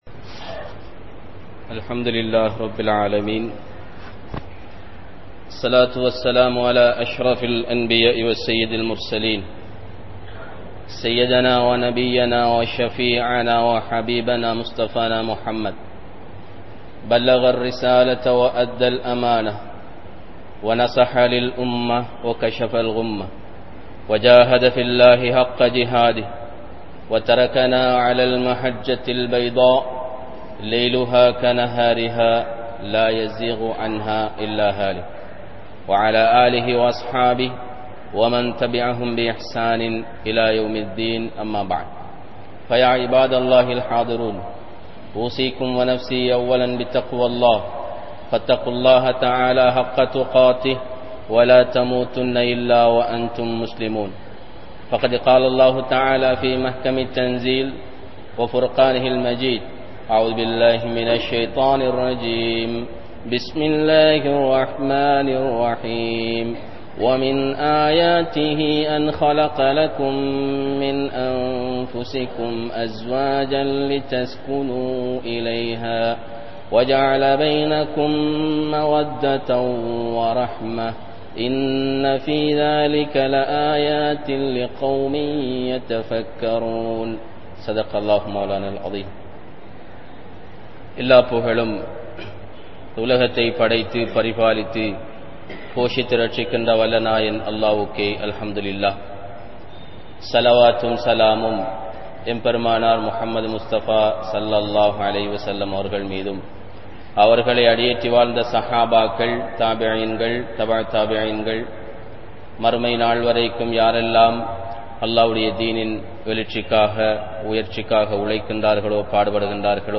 Ethatku Thirumanam? (எதற்கு திருமணம்?) | Audio Bayans | All Ceylon Muslim Youth Community | Addalaichenai